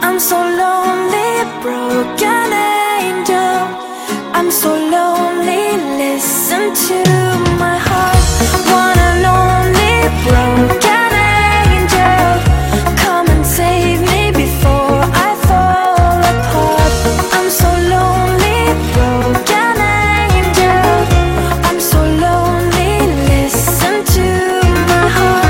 Catégorie Marimba Remix